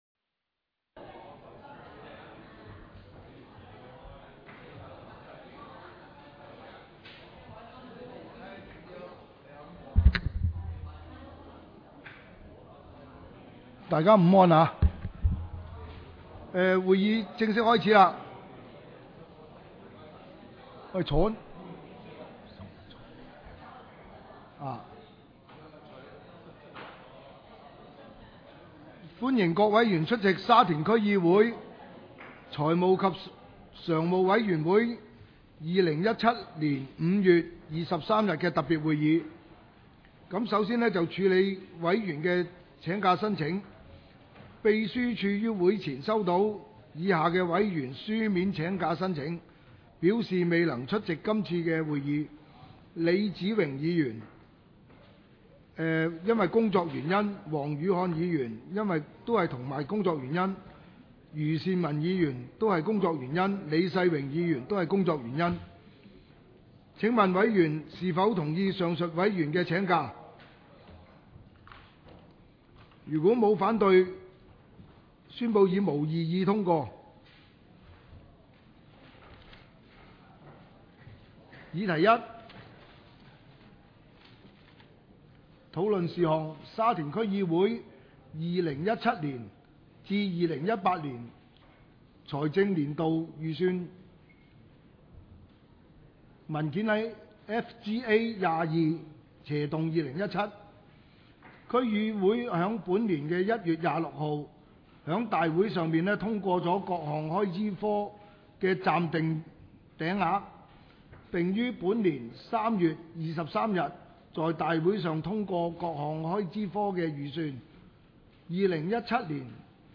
委员会会议的录音记录
财务及常务委员会特别会议会议 日期: 2017-05-23 (星期二) 时间: 下午2时30分 地点: 议程 讨论时间 I 沙田区议会二零一七至二零一八财政年度修订预算 00:16:52 全部展开 全部收回 议程:I 沙田区议会二零一七至二零一八财政年度修订预算 讨论时间: 00:16:52 前一页 返回页首 如欲参阅以上文件所载档案较大的附件或受版权保护的附件，请向 区议会秘书处 或有关版权持有人（按情况）查询。